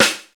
SNR XC.SNR00.wav